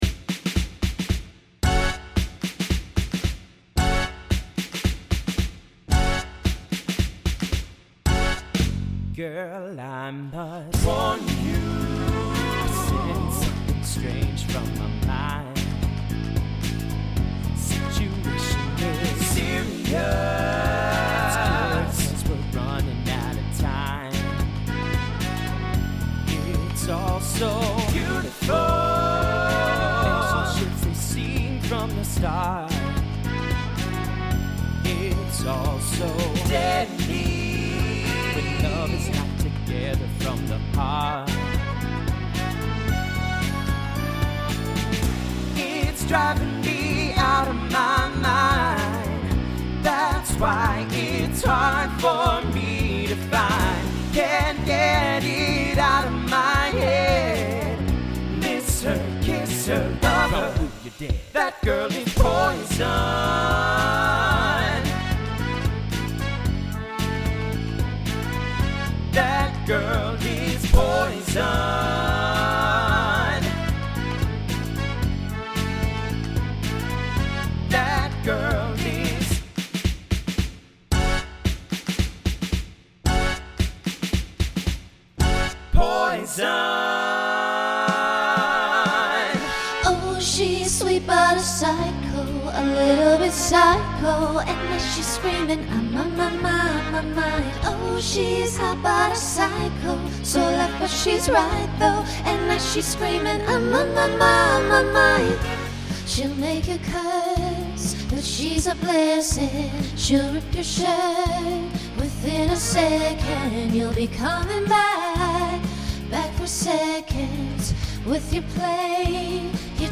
TTB/SSA
Genre Pop/Dance
Transition Voicing Mixed